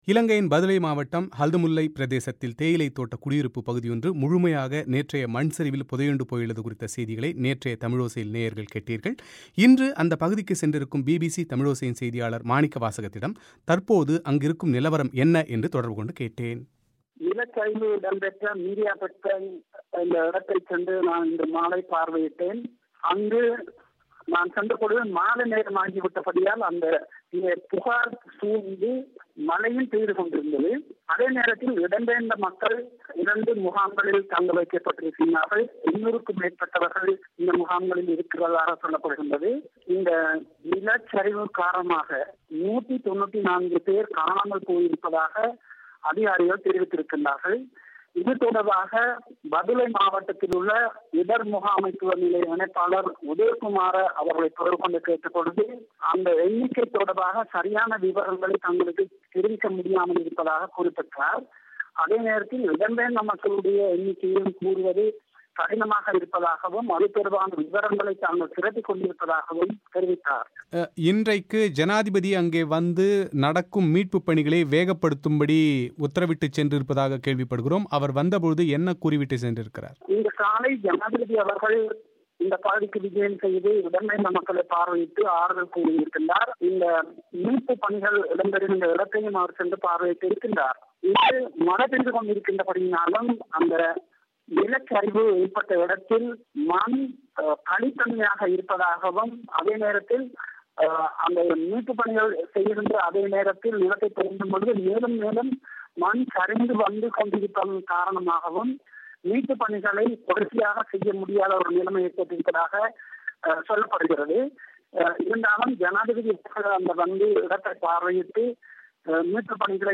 அங்கு மீட்புப் பணிகளின் நிலைமைகள் குறித்து அவர் வழங்கும் தகவல்களின் ஒலித்தொகுப்பை இங்கு கேட்கலாம்.